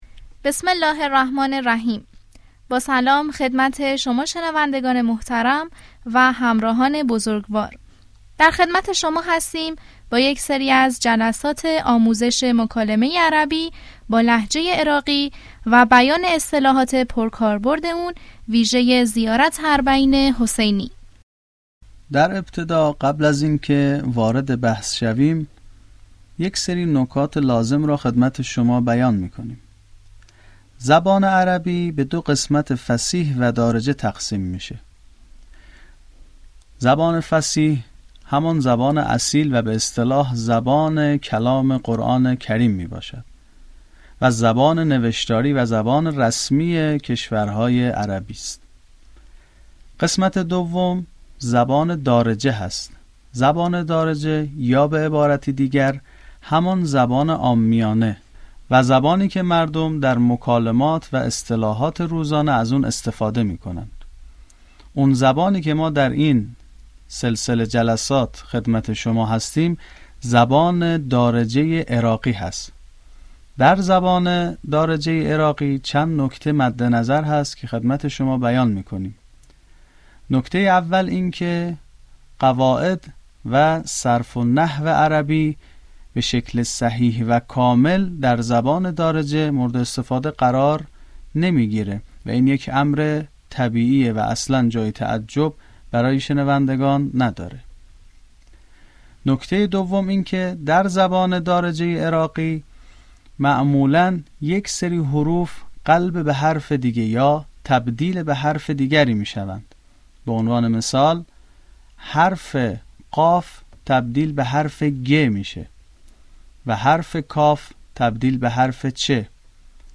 آموزش مکالمه عربی به لهجه عراقی - مقدمه